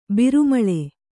♪ biru maḷe